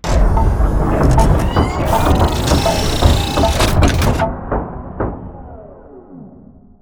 repair.wav